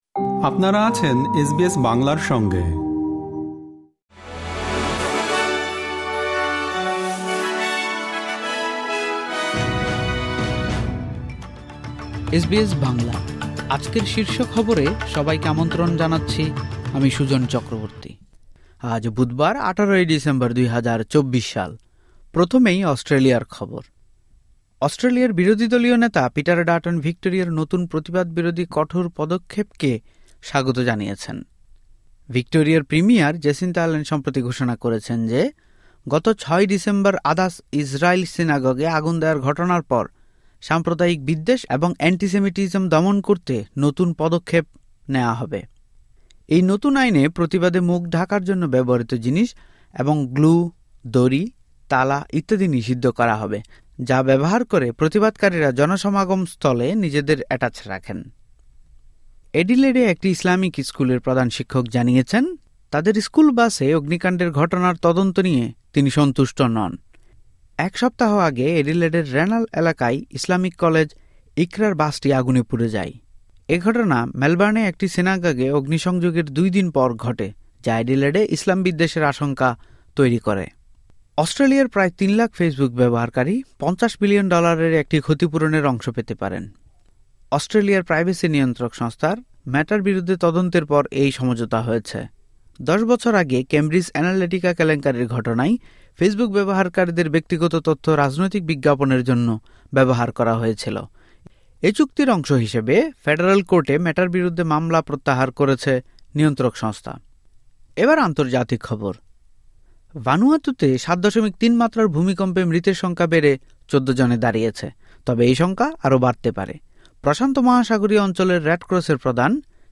এসবিএস বাংলা শীর্ষ খবর: ১৮ ডিসেম্বর, ২০২৪